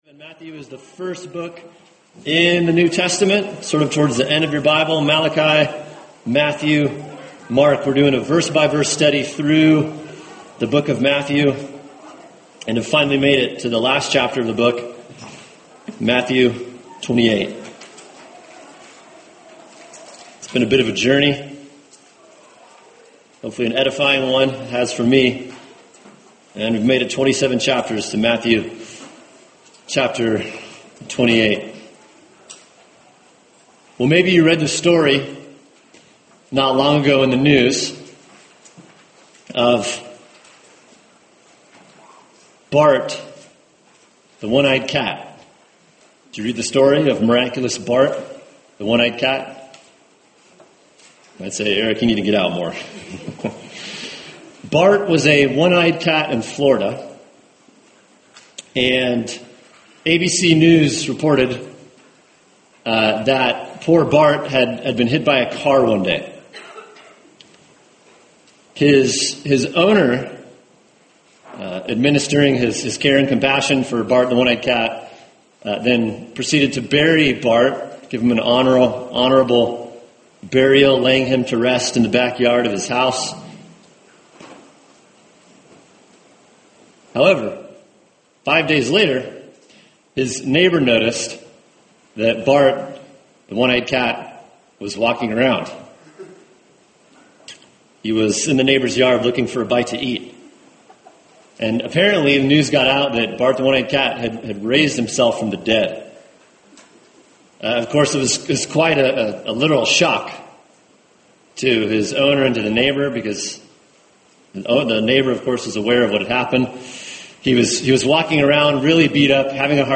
[sermon] Matthew 28:1-7 The Resurrection of Christ, Part 1 | Cornerstone Church - Jackson Hole